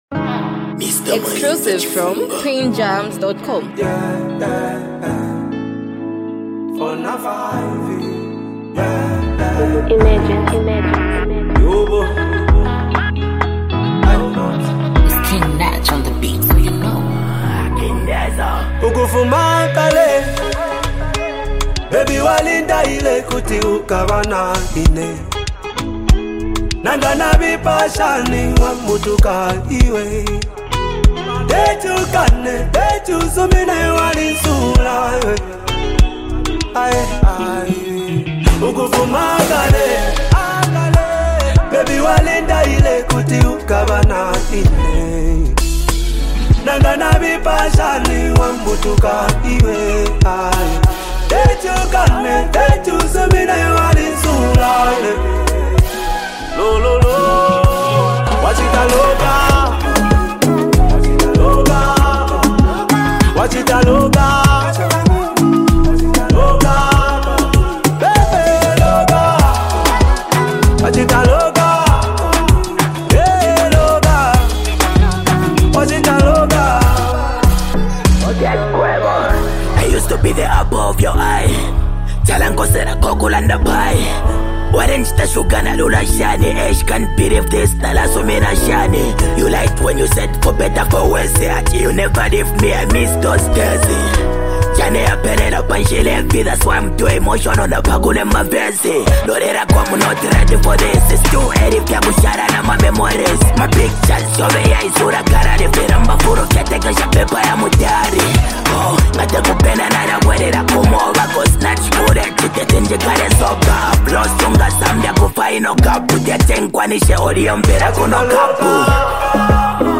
Zambian music
rap duos